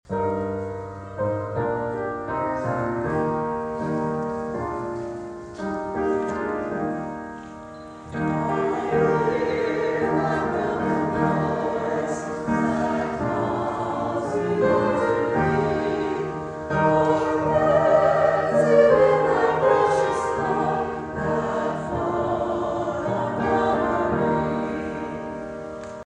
St. David’s Day concert fills Emporia Presbyterian Church West Campus with song
Emporia’s Presbyterian Church West Campus was filled with song — and a lot of people — for the 134th Saint David’s Day concert.
Sunday’s concert saw close to 100 people brave the cold and intermittent ice pellets that moved in before heavier sleet covered the area.